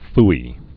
(fē)